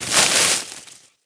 vines.ogg